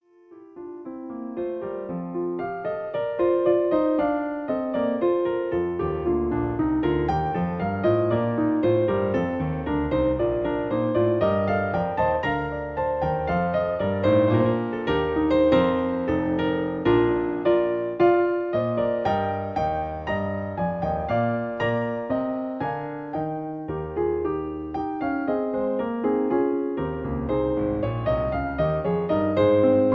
Original Key (E flat). Piano Accompaniment